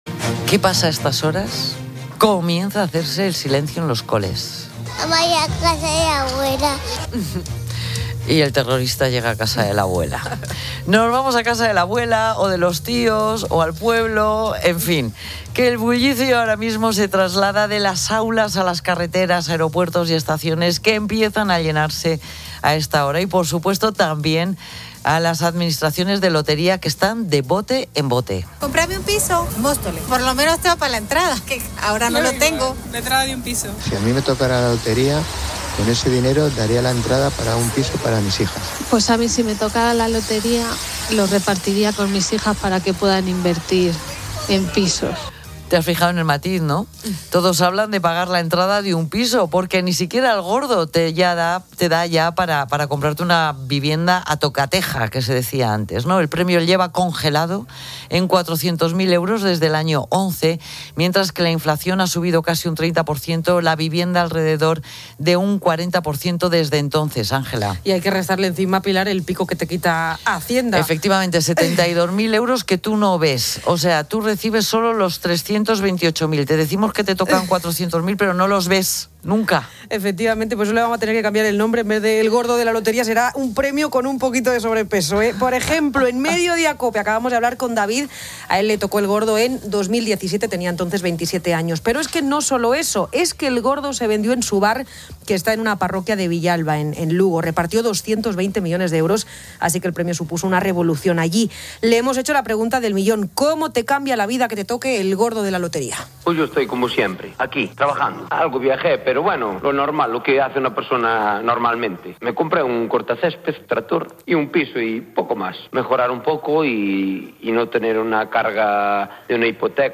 Pilar García de la Granja, experta económica
Pilar García de la Granja analiza las principales noticias económicas en Mediodía COPE